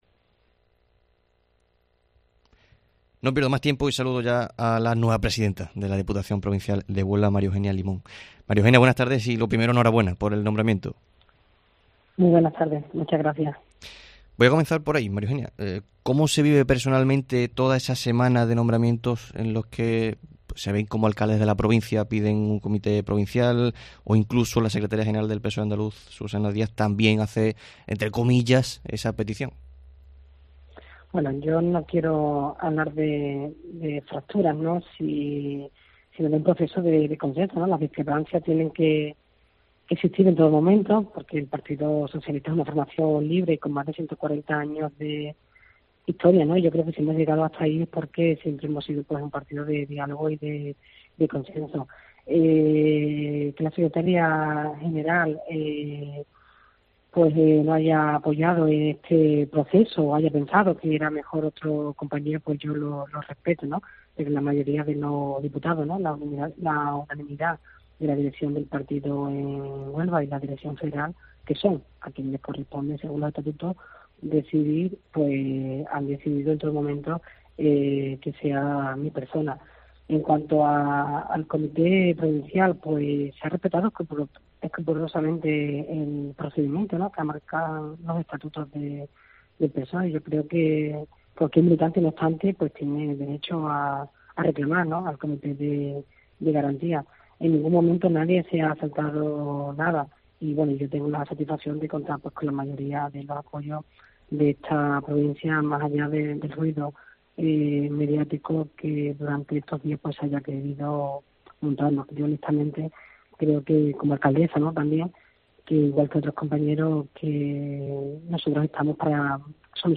La nueva presidenta de la Diputación Provincial de Huelva ha atendido a COPE en el Herrera en COPE Huelva de este martes para hablar de su reciente nombramiento al frente del ente.